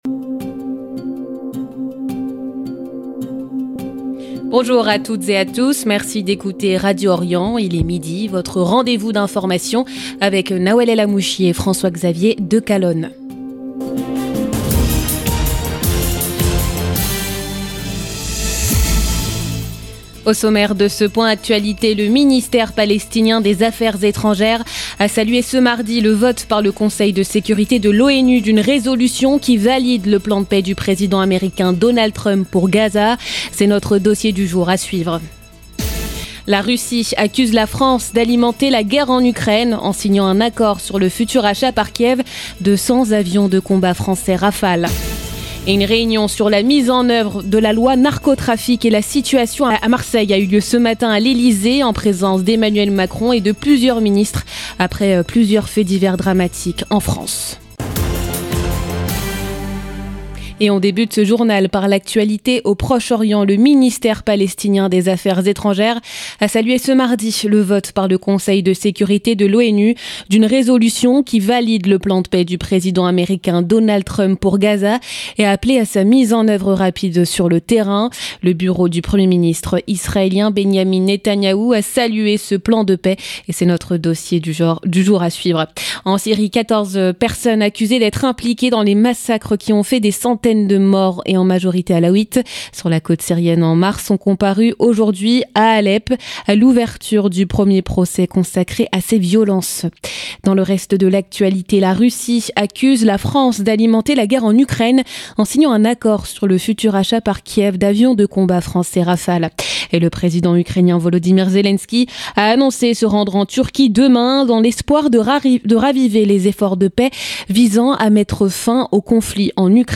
JOURNAL DE MIDI